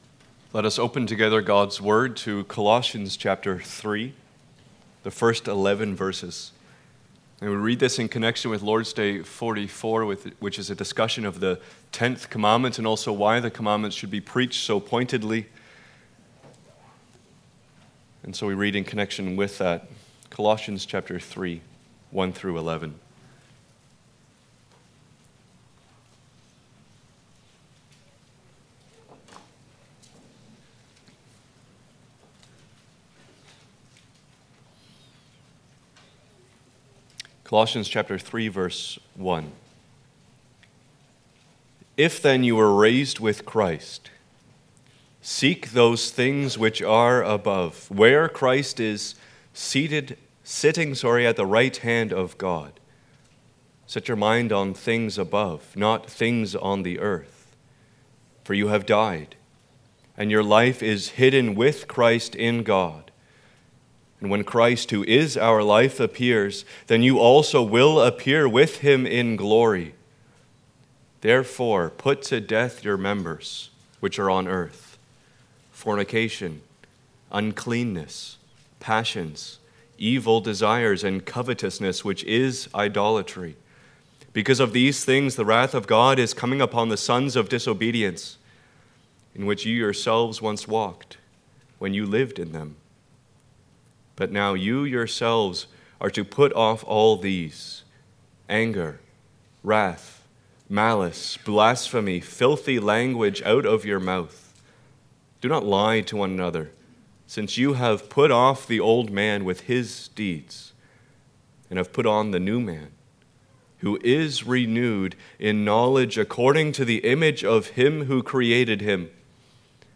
General Passage: Colossians3:1-11 Service Type: Sunday Afternoon « Rejoice!